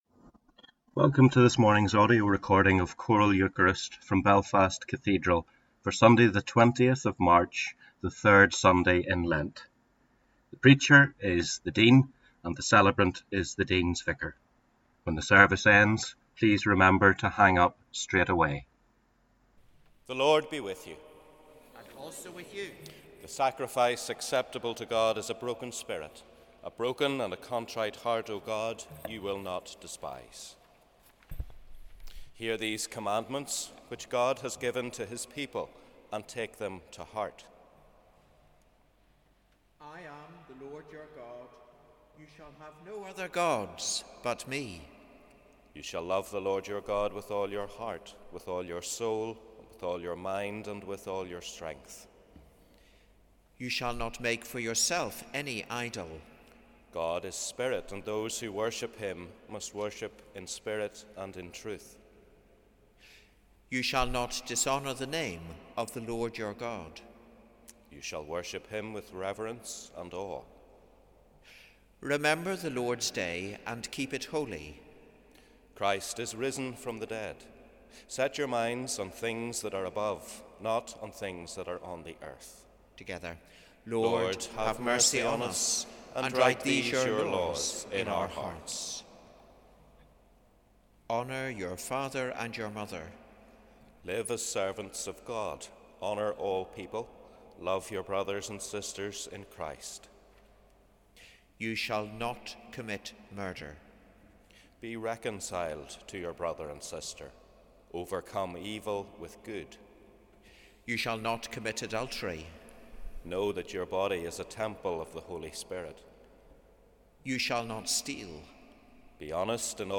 Sunday 20 March Choral Eucharist Download Sunday 20 March MU Service Download Eucharist Audio Recording Download Share this Article